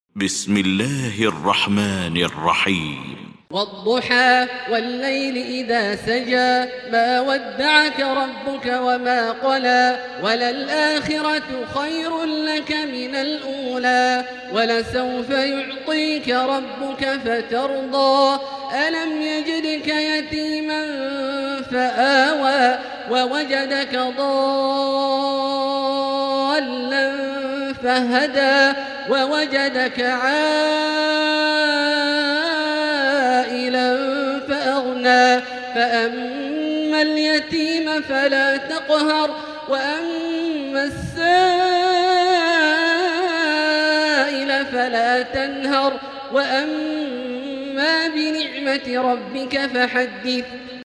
المكان: المسجد الحرام الشيخ: فضيلة الشيخ عبدالله الجهني فضيلة الشيخ عبدالله الجهني الضحى